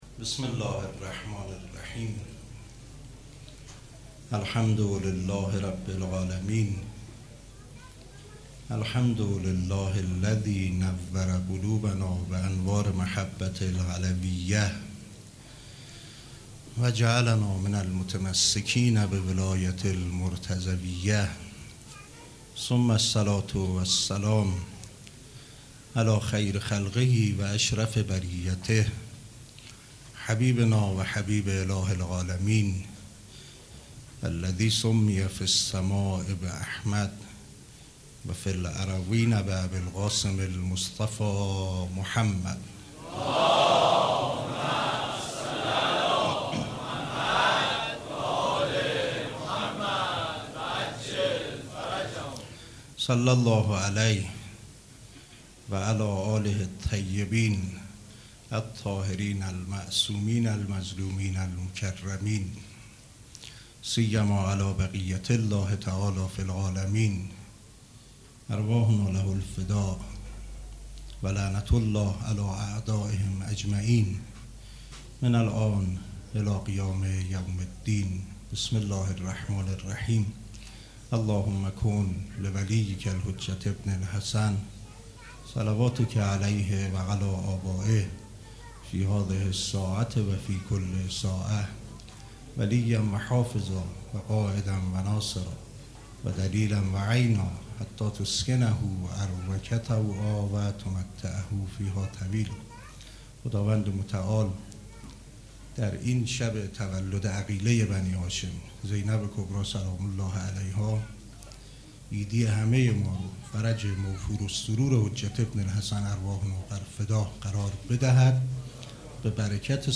سخنرانی
هیئت ریحانه الحسین سلام الله علیها